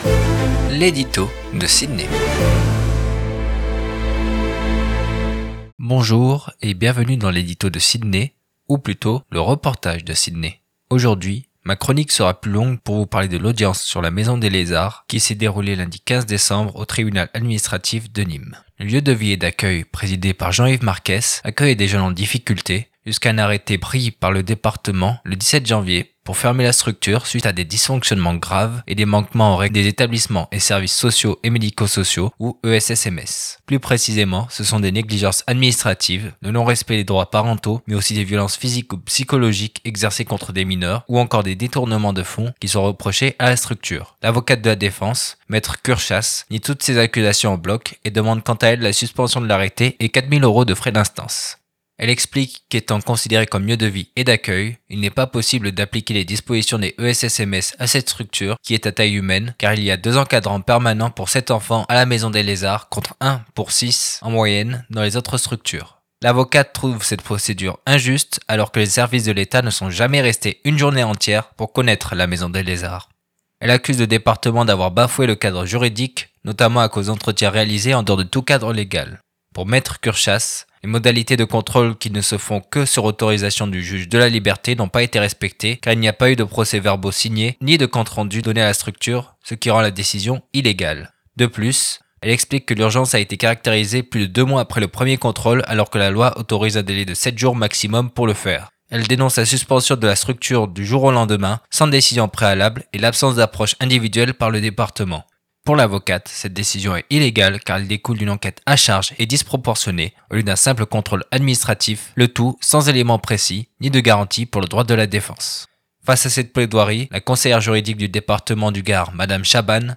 Dans son édito exceptionnellement en format reportage